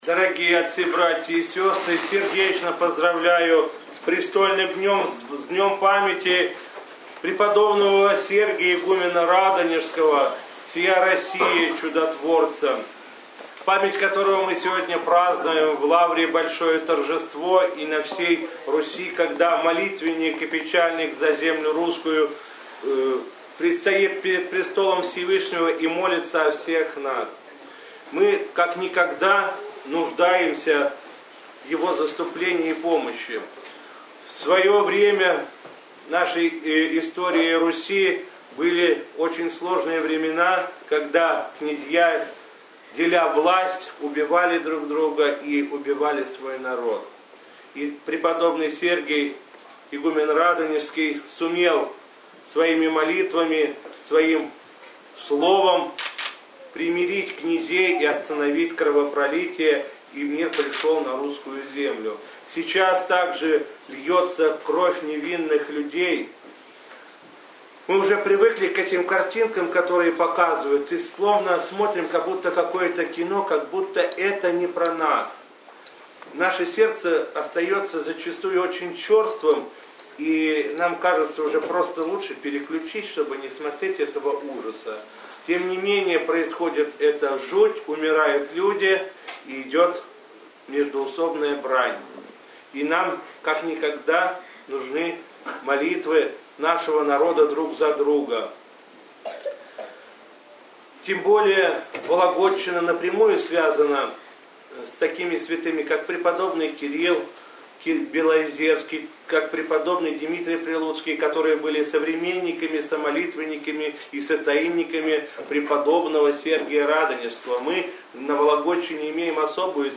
8 октября 2015 года, в день памяти преподобного Сергия, игумена Радонежского, всея России чудотворца, Глава Вологодской митрополии митрополит Вологодский и Кирилловский Игнатий совершил Божественную литургию в храме преподобного Сергия Радонежского в микрорайоне льнокомбината Вологды.
По окончании богослужения владыка Игнатий поздравил с праздником молящихся и обратился к ним с архипастырским словом, в котором, в частности, сказал: